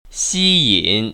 [xīyĭn] 씨인  ▶